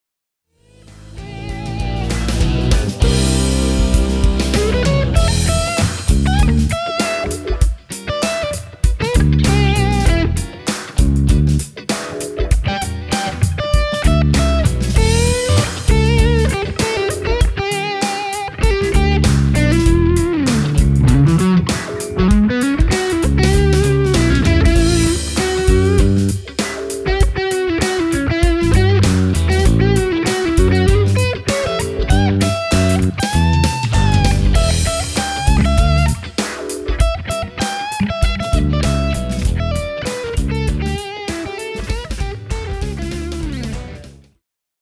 Tässä on kolme lyhyttä pätkää, joissa soitan soolo-osuudet JS-10:n omien eBand-biisien päälle:
Boss JS-10 – Funky Groove